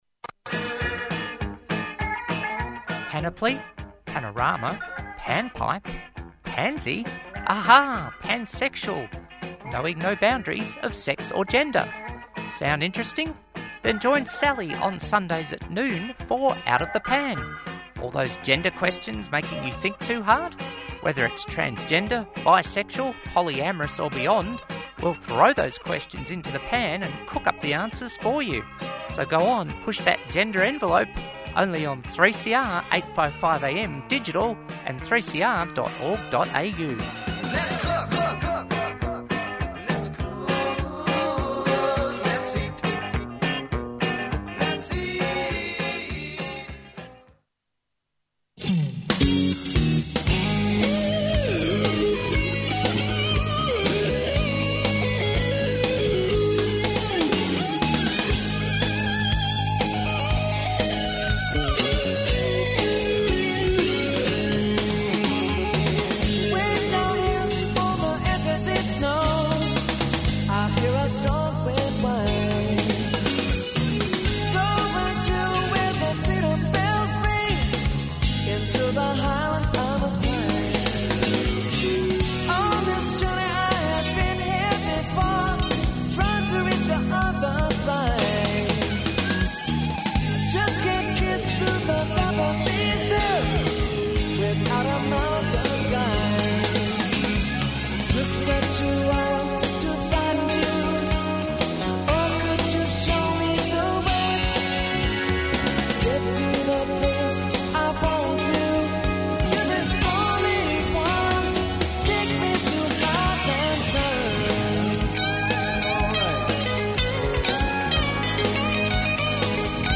The conversation opens by examining the multifaceted nature of disability inclusion — highlighting the need for accessible environments and, importantly, organisational cultures that foster belonging and r…